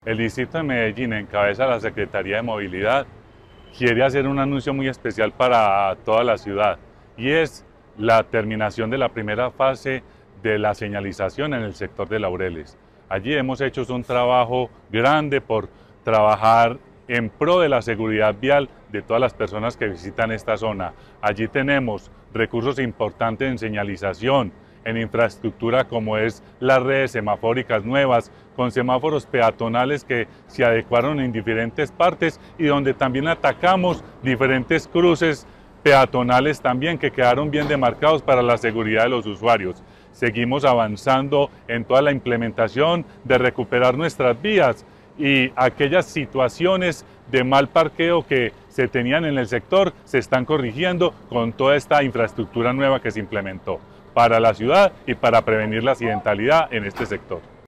Declaraciones secretario (e) de Movilidad, Luis Eduardo Echeverri Con el propósito de mejorar la movilidad y optimizar la infraestructura en el territorio, la Alcaldía de Medellín priorizó una intervención integral en la comuna 11-Laureles/Estadio.
Declaraciones-secretario-e-de-Movilidad-Luis-Eduardo-Echeverri.mp3